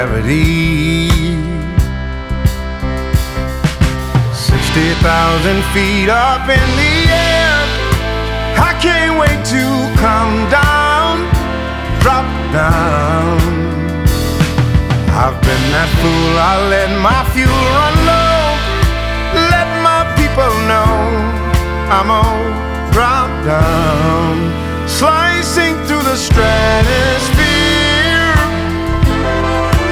• Jazz